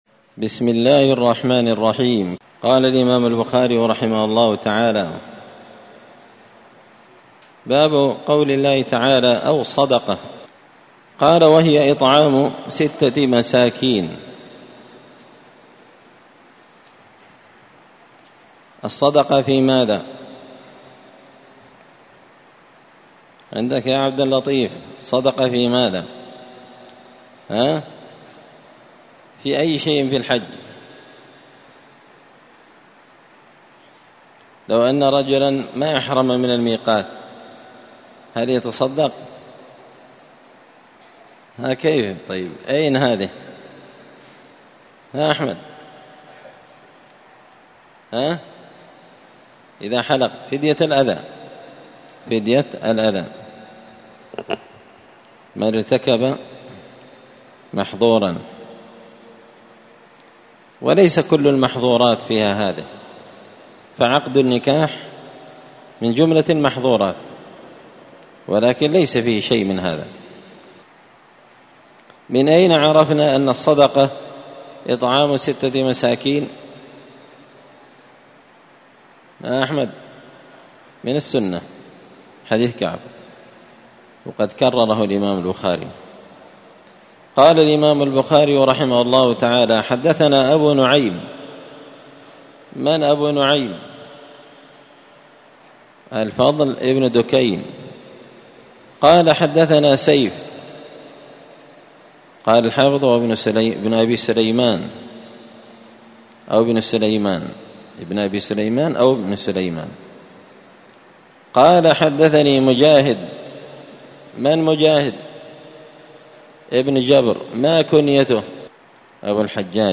مسجد الفرقان قشن المهرة اليمن